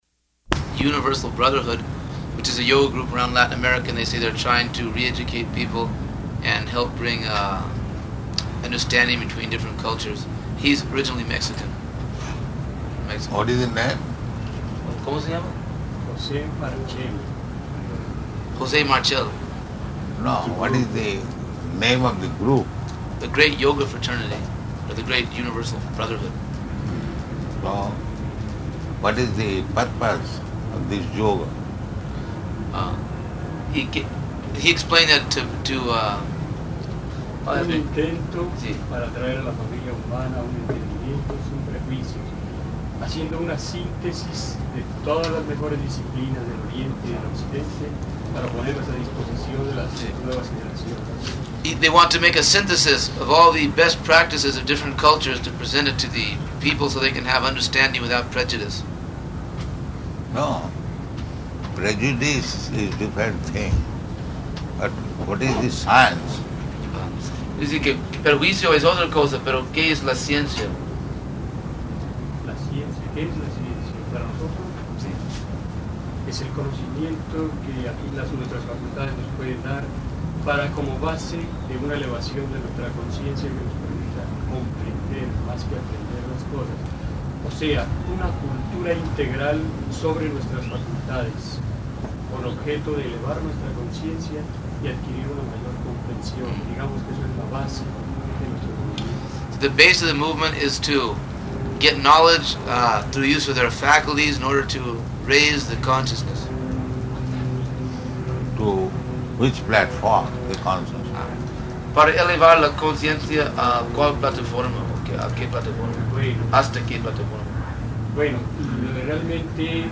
Room Conversation with Metaphysics Society
Type: Conversation
Location: Caracas